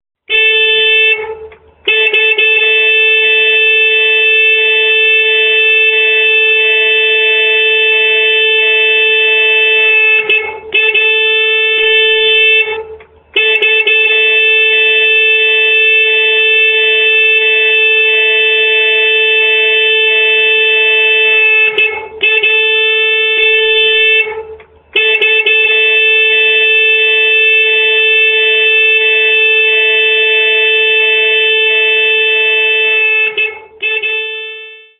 الالات واصوات